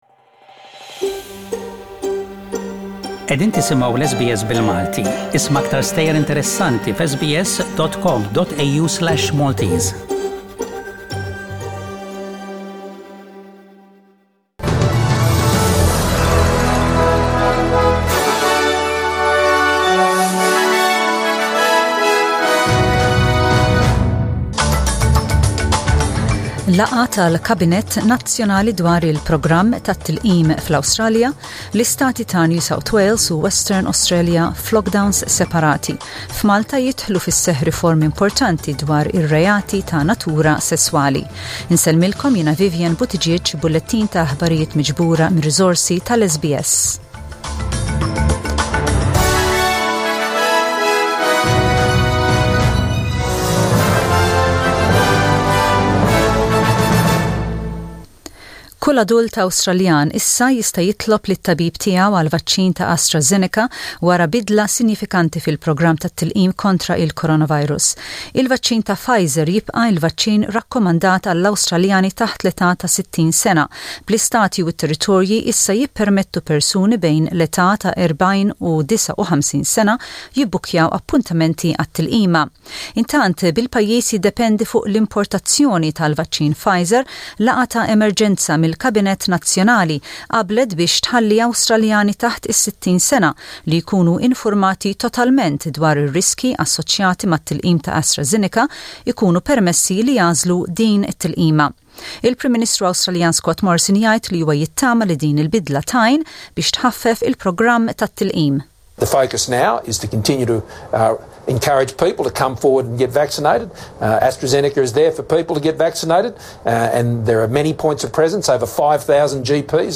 SBS Radio | News in Maltese: 29/06/21